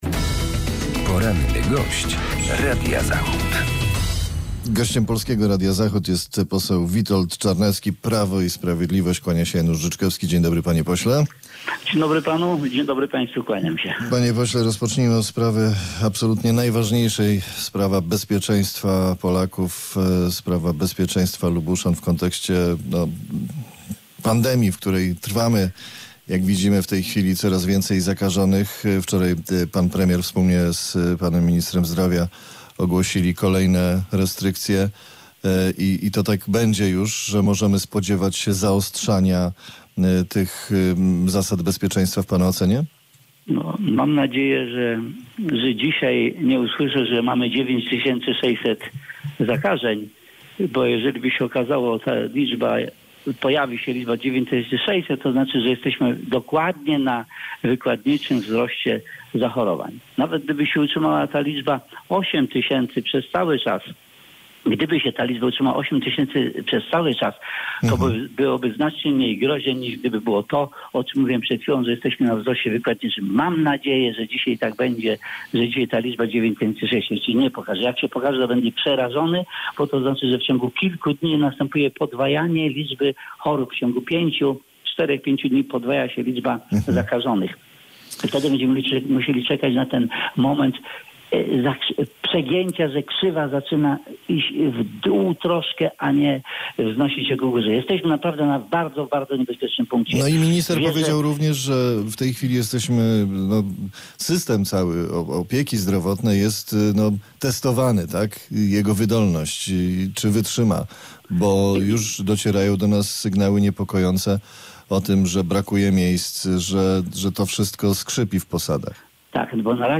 Z posłem Prawa i Sprawiedliwości rozmawia